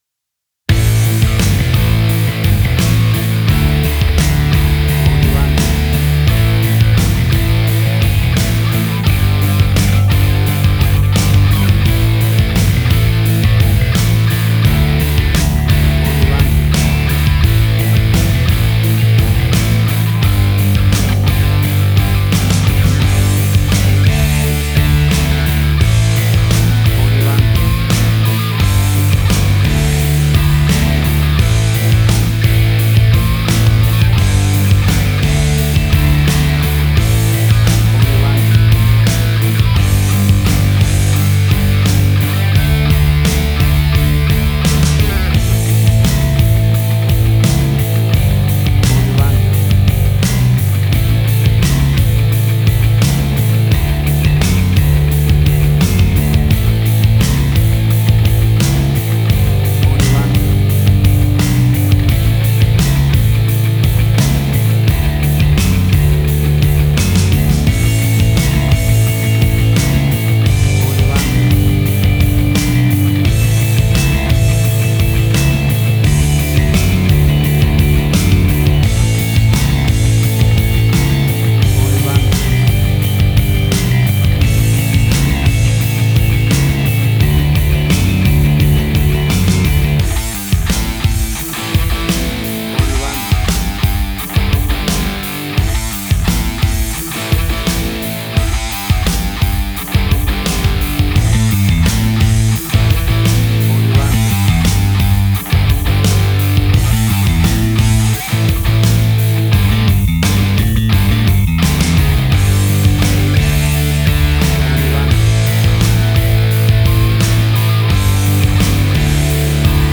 WAV Sample Rate: 16-Bit stereo, 44.1 kHz
Tempo (BPM): 86